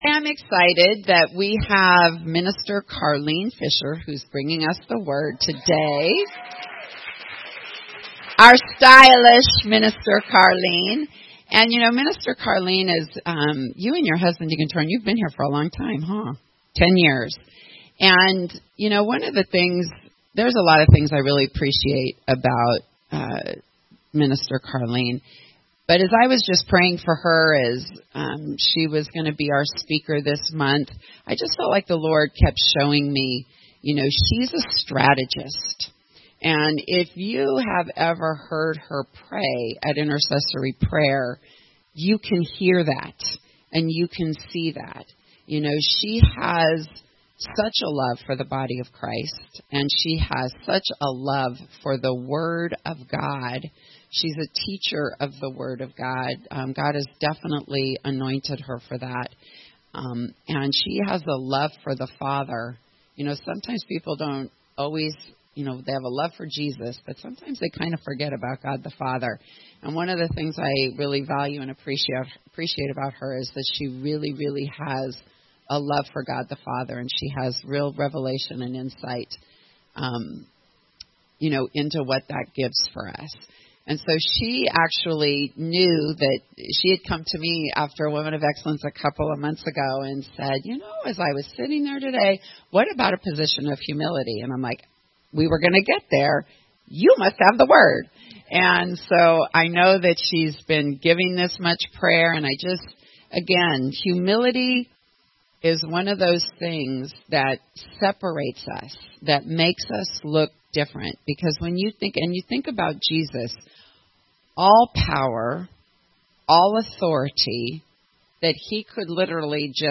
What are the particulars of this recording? There are a couple of moments when there is a bit of silence in the audio. I wrote a couple of things on the white board as follows: After the fall, Adam and Eve’s relationship was severed causing shame.